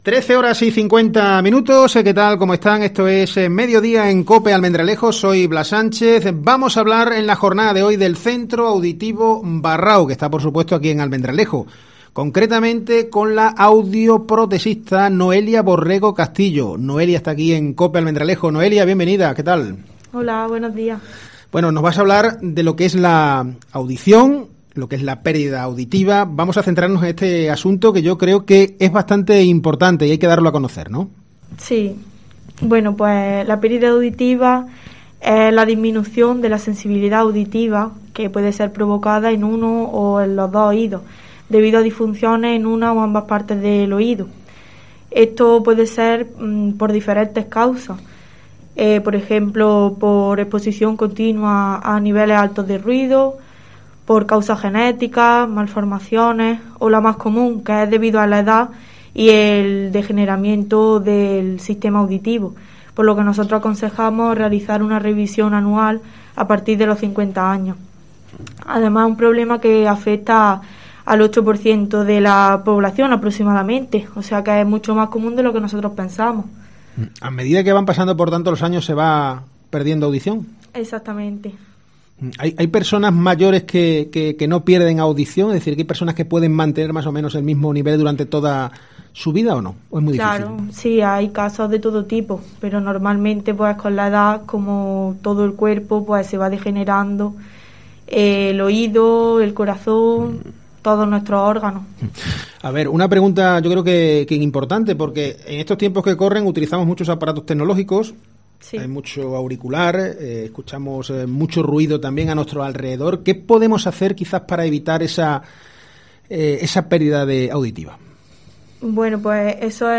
En COPE hemos entrevistado